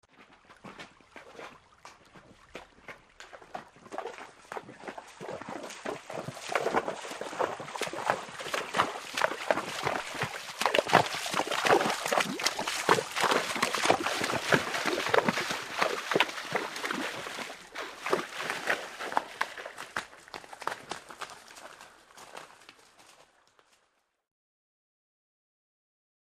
Horse Through Medium Water; Steady, Light Water In Stream With Horse Walking On Wet Rocks And Into Water And Away. Medium.